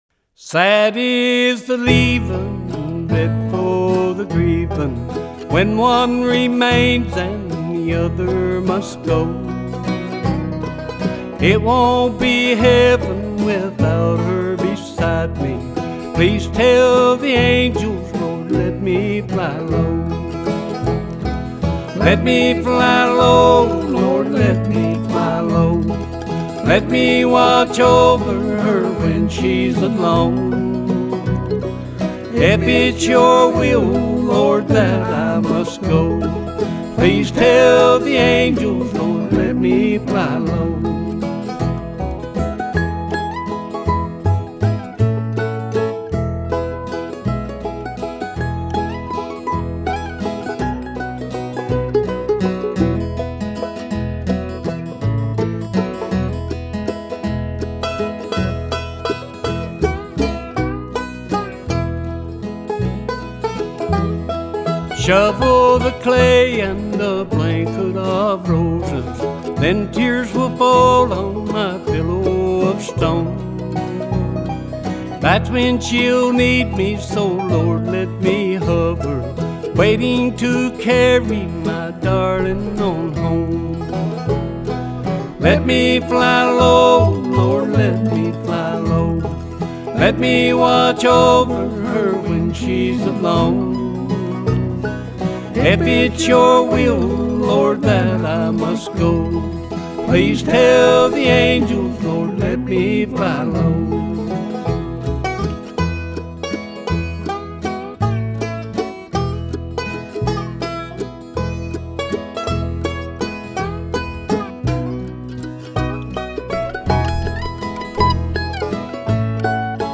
This is American traditional music at its very best.